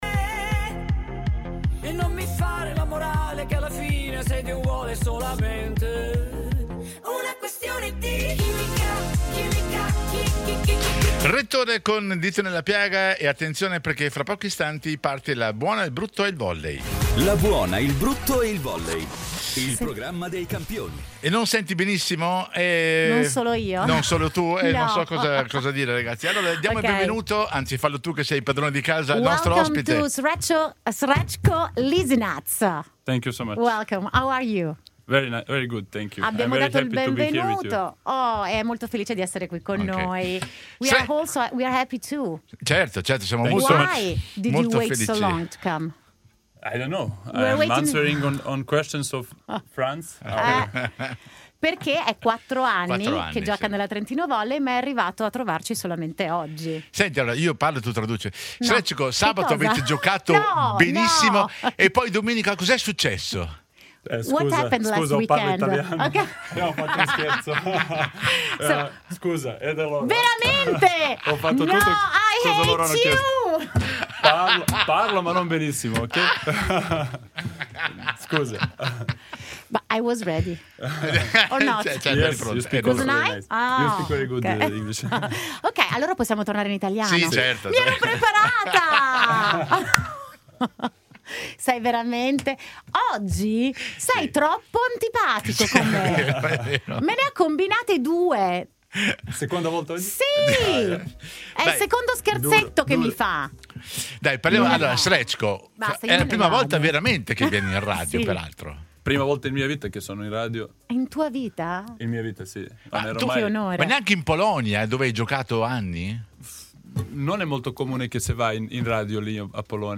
Ascolta l'intervista integrale Trentino Volley Srl Ufficio Stampa